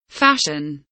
fashion kelimesinin anlamı, resimli anlatımı ve sesli okunuşu